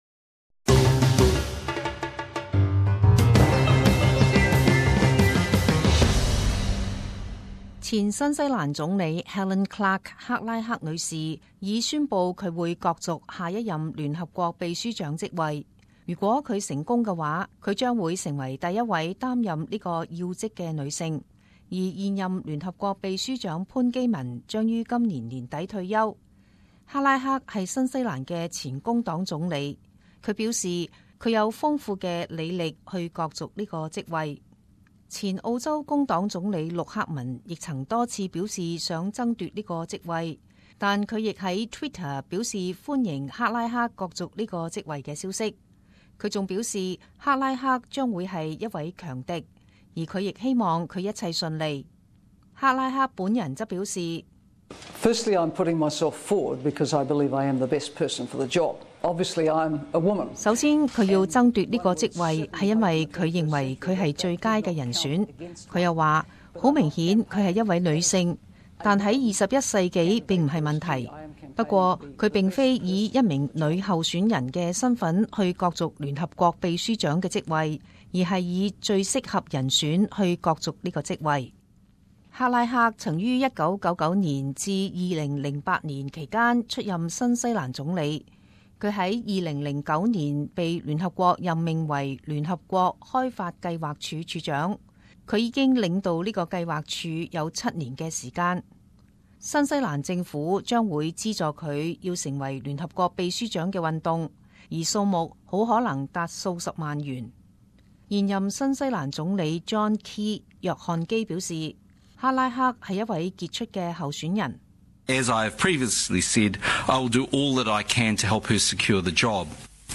時事報導 - 前新西蘭總理克拉克角逐聯合國秘書長職位